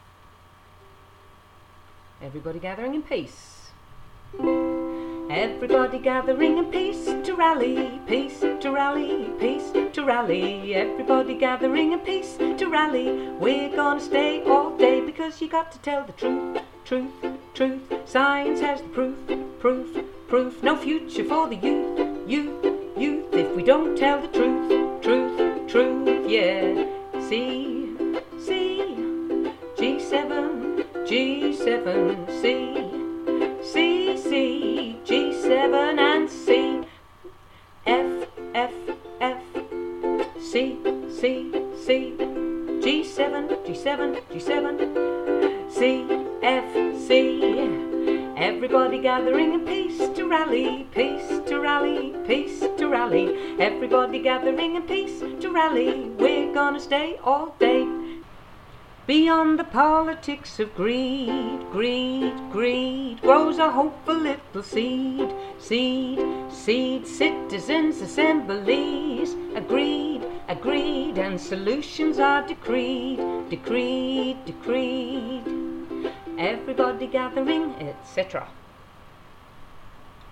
Here’s a rough of the music and chords